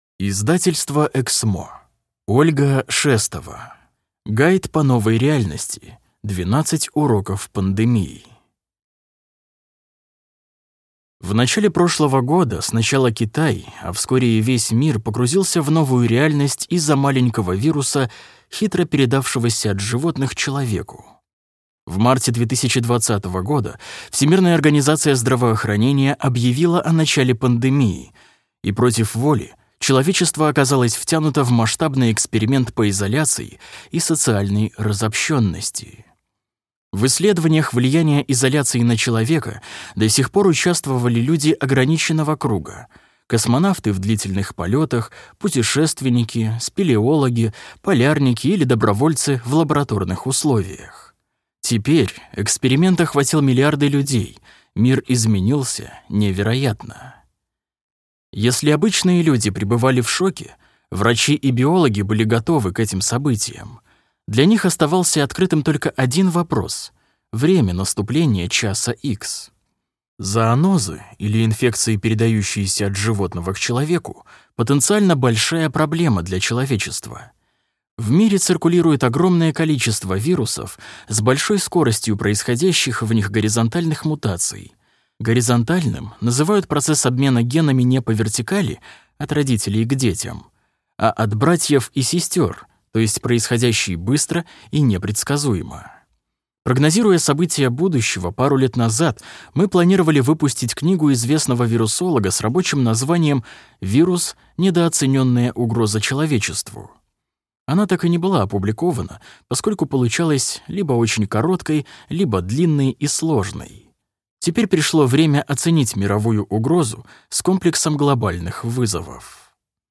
Аудиокнига Гайд по новой реальности: 12 уроков пандемии | Библиотека аудиокниг